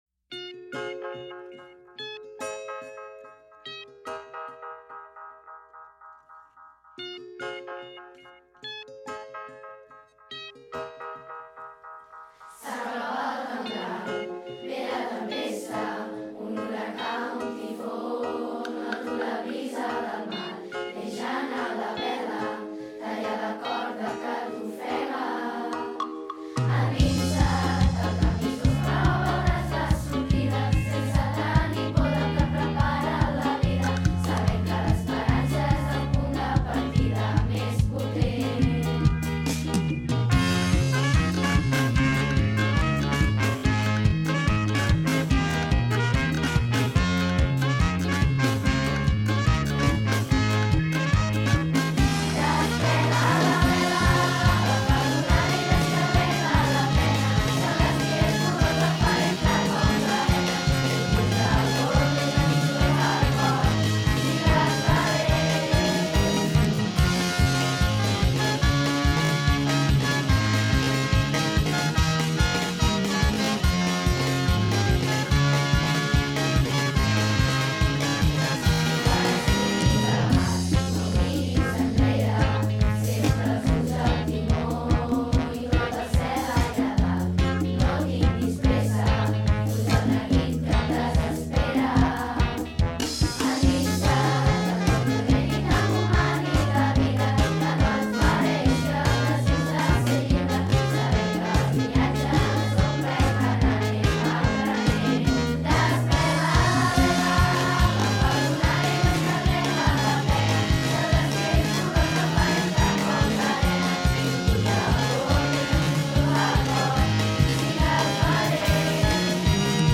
Els Massagrans hem començat les sessions de música amb molta il·lusió.